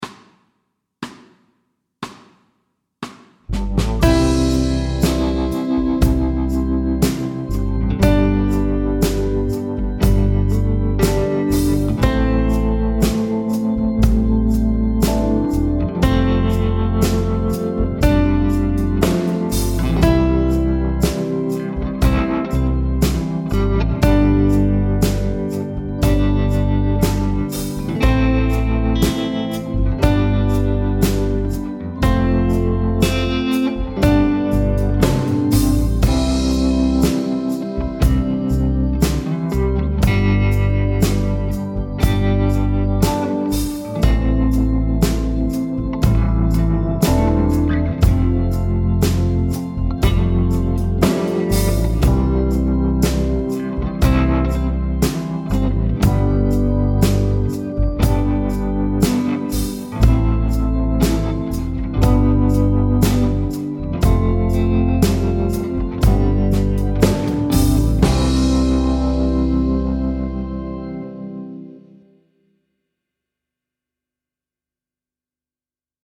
Slow C instr (demo)
Rytmeværdier: 1/1- og 1/2 noder.